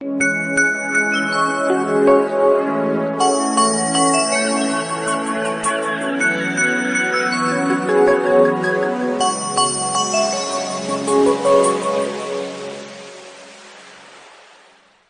• Качество: 320, Stereo
спокойные
без слов
красивая мелодия
шум моря